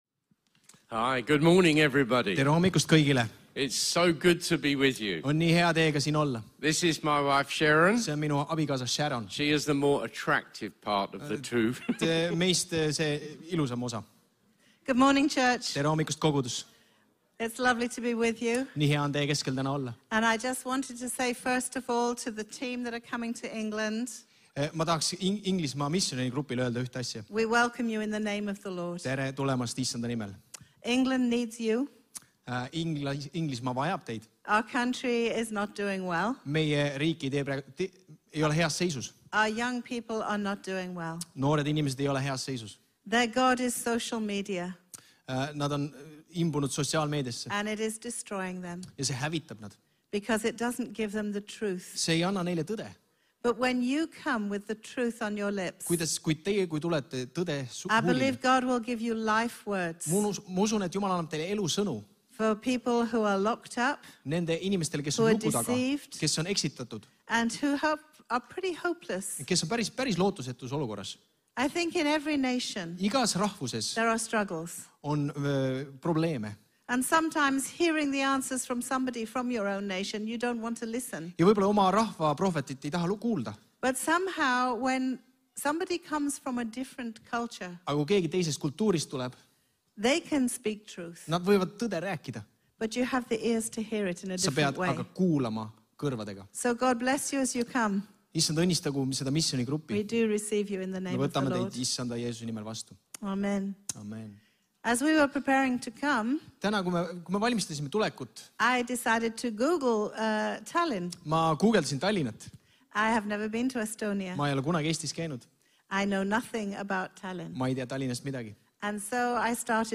Kristlik ja kaasaegne kogudus Tallinna kesklinnas.
1251_jutlus.mp3